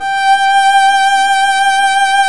Index of /90_sSampleCDs/Roland - String Master Series/STR_Violin 1 vb/STR_Vln1 _ marc